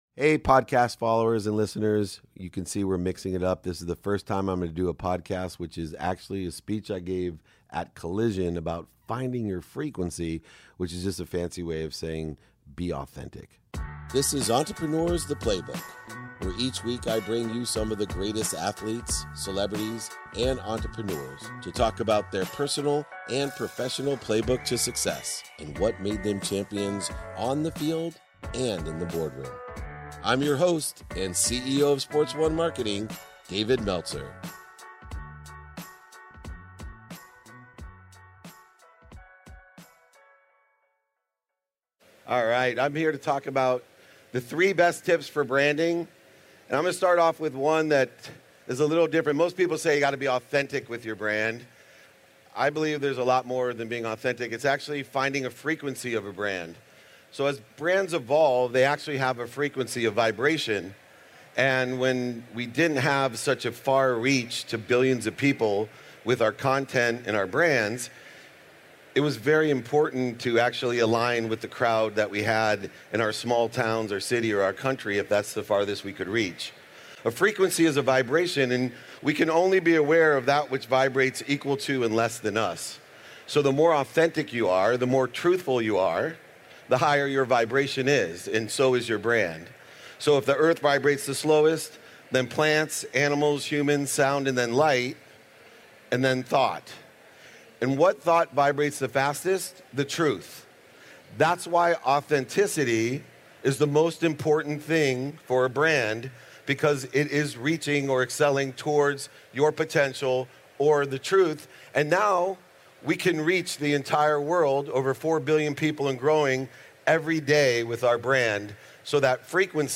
How To Build a Brand That Lasts | Collision Conference Keynote